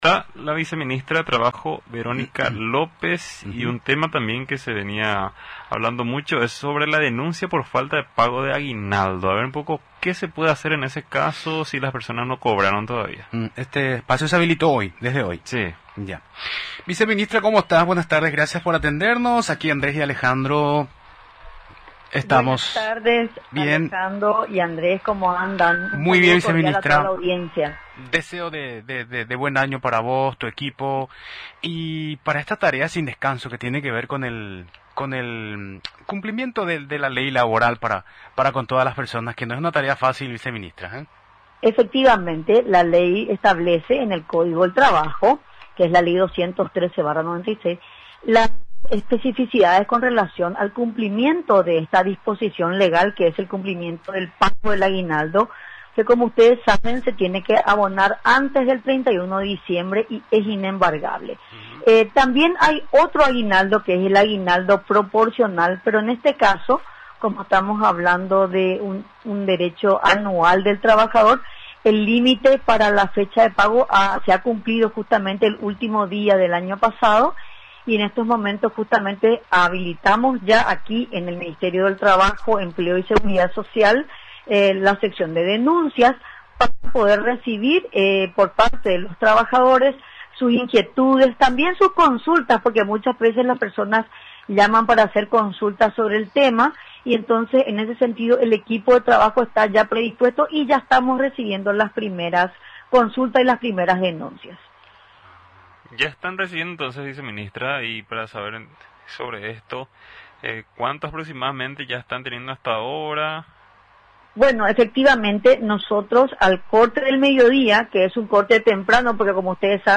A partir de este viernes 2 de enero, el Ministerio de Trabajo habilita el periodo de recepción de denuncias por falta de pago del aguinaldo, informó la viceministra de Trabajo, Verónica López.
Explicó, durante la entrevista en Radio Nacional del Paraguay, que de acuerdo con el artículo 243 del Código del Trabajo, la remuneración anual complementaria es de carácter obligatorio y debe ser abonada por el empleador antes del 31 de diciembre de cada año.